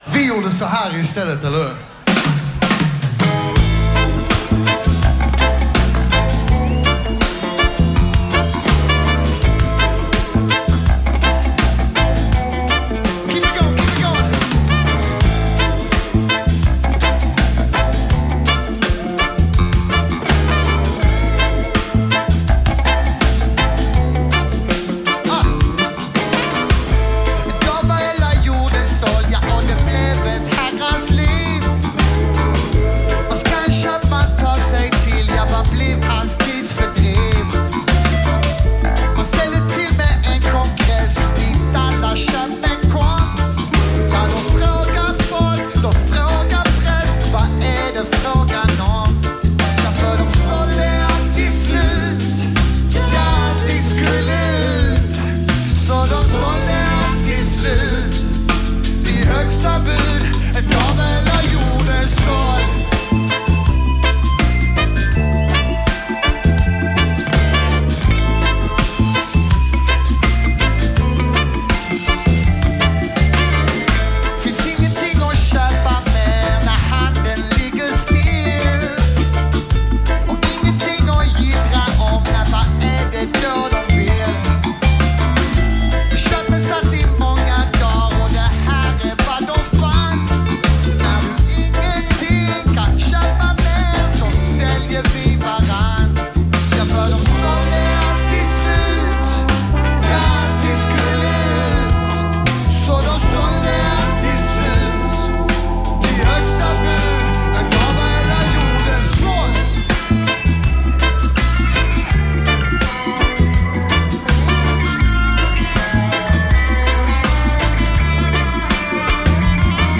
live at Öland Roots Festival 2018
Live At Öland Roots Festival
Skanst/Reggae/Svenska Hip-Hop